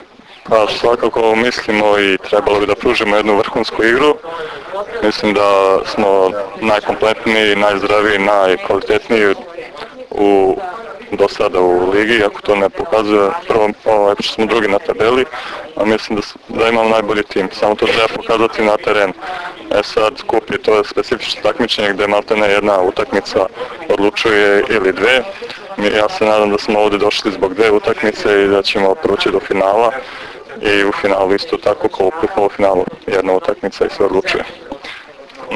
U pres sali SD Crvena zvezda danas je održana konferencija za novinare povodom Finalnog turnira 46. Kupa Srbije u konkurenciji odbojkaša.
IZJAVA